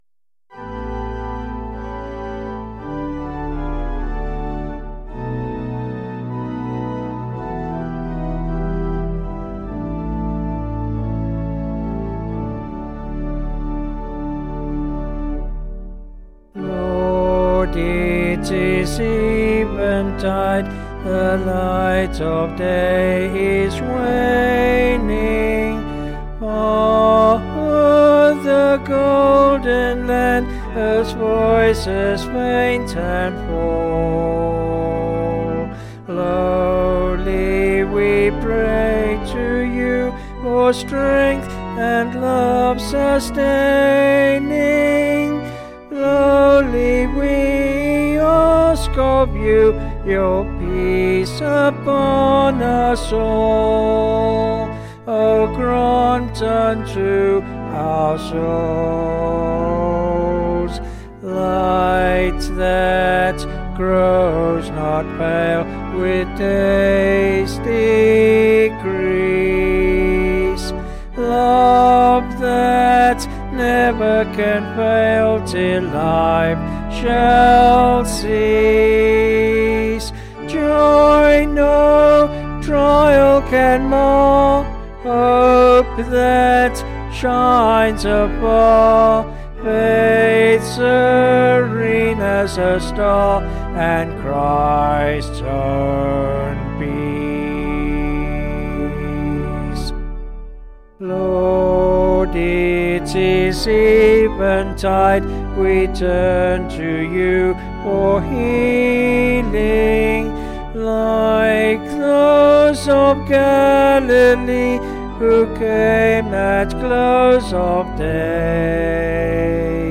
Vocals and Organ   266.8kb Sung Lyrics